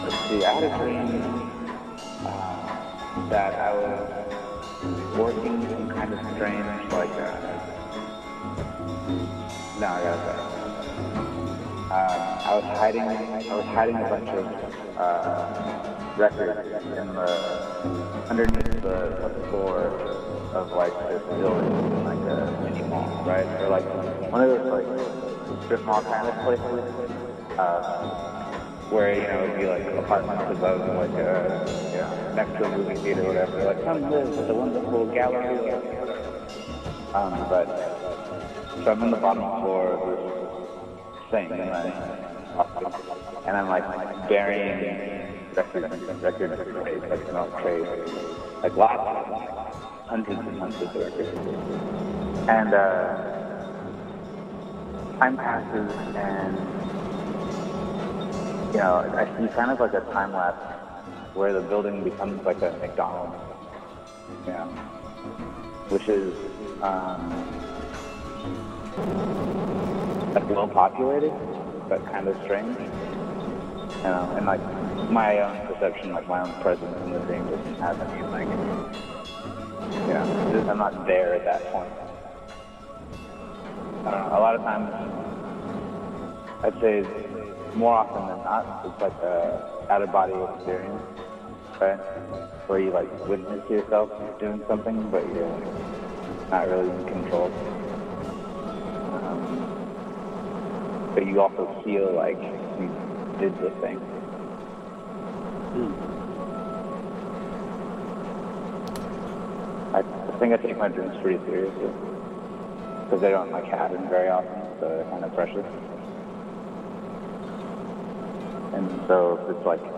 A duet driven collage
In a stew of intimate electronics, bad poetry, and tender murk, we swap tongues and reach for more.